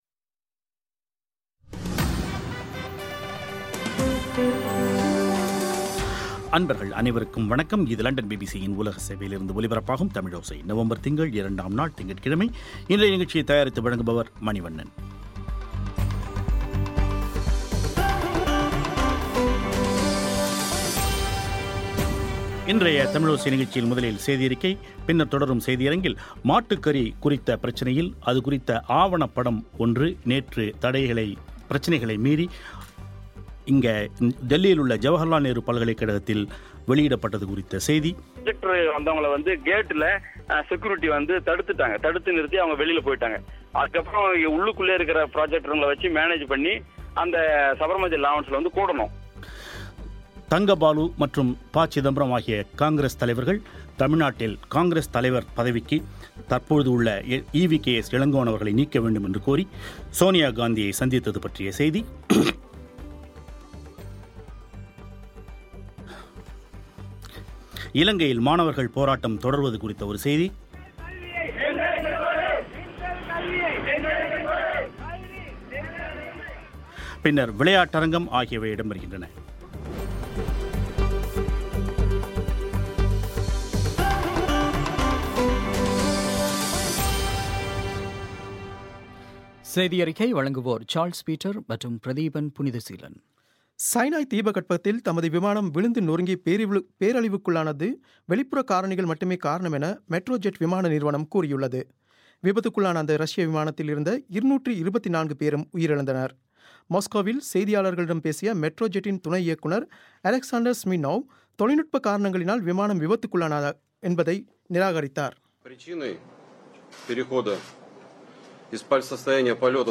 முன்னாள் அமைச்சர் தங்கபாலுவுடன் ஒரு பேட்டி